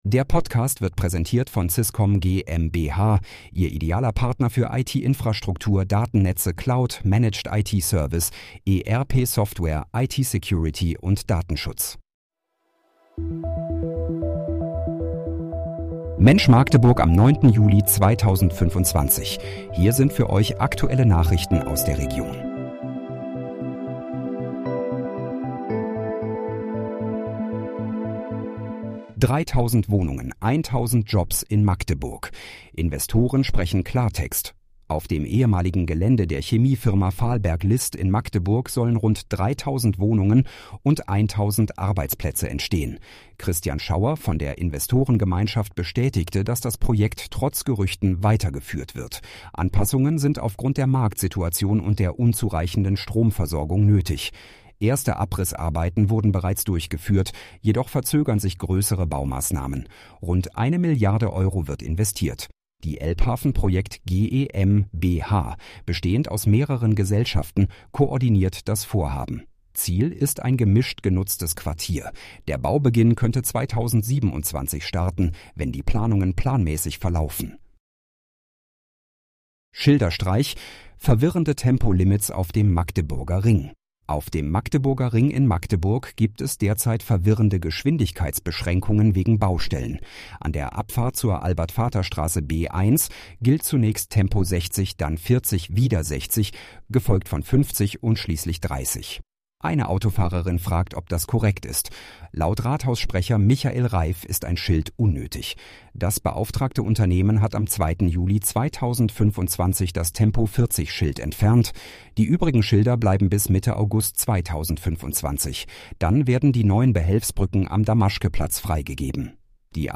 Mensch, Magdeburg: Aktuelle Nachrichten vom 09.07.2025, erstellt mit KI-Unterstützung
Nachrichten